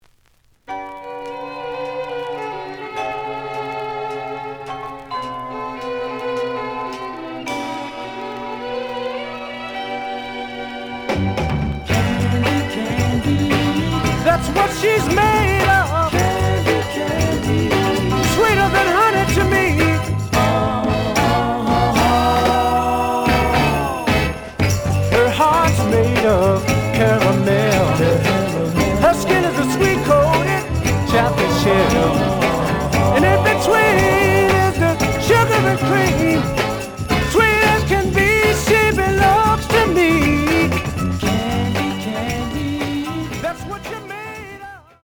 The audio sample is recorded from the actual item.
●Genre: Soul, 60's Soul
Some click noise on middle of A side.